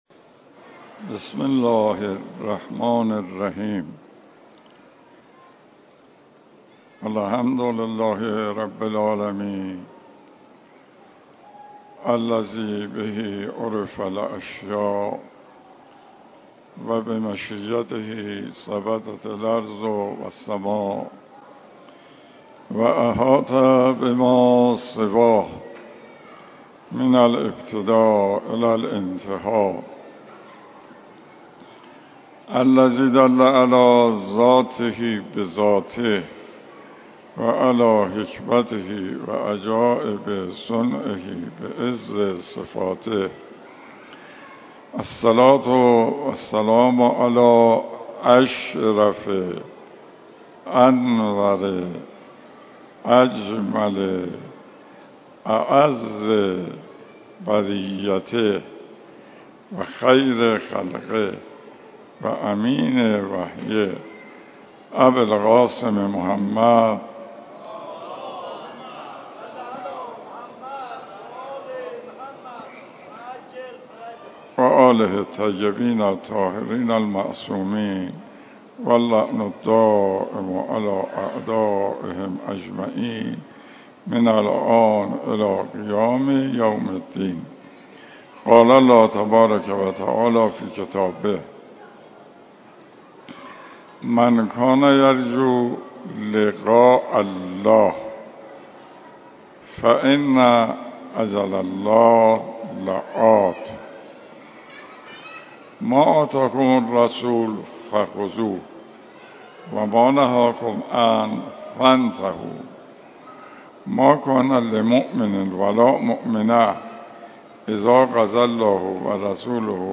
جلسات درس اخلاق